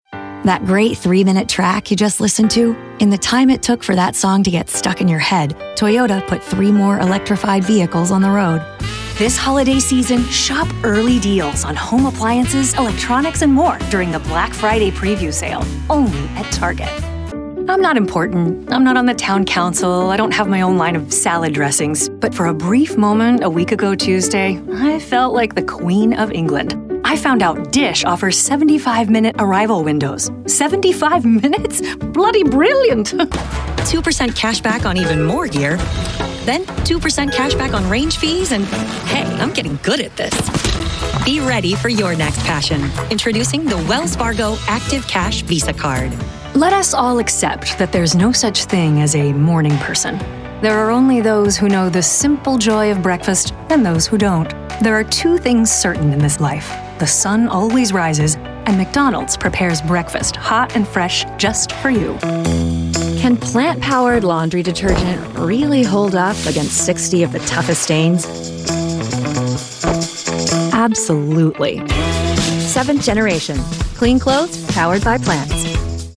Seasoned voiceover actor with a broad range of skills
Commercial Demo